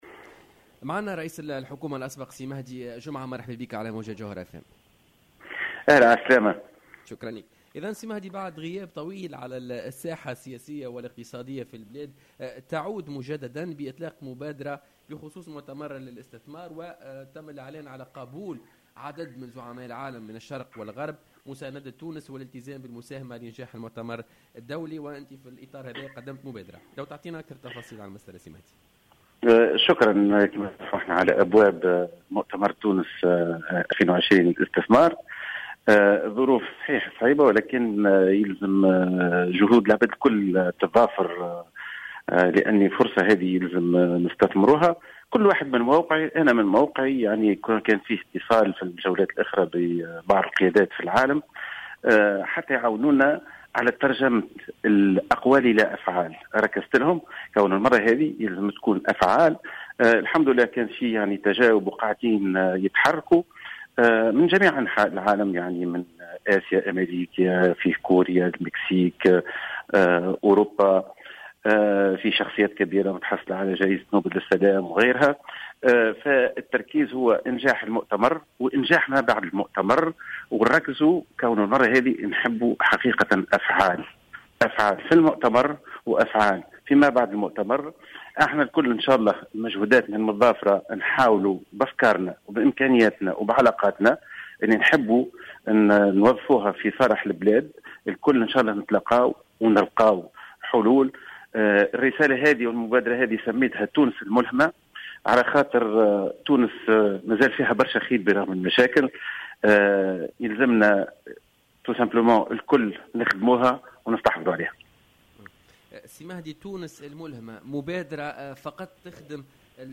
L'ancien chef du gouvernement Mehdi Jomaa a annoncé vendredi au micro de Jawhara FM, le lancement de son initiative Tunisie l'inspirante qui vise...
Dans une déclaration exclusive accordée à Jawhara FM, Jomaa a assuré que des leaders de pays occidentaux et orientaux ont confirmé leur présence au forum Tunisia 2020 et ont réitéré leur soutien à la Tunisie.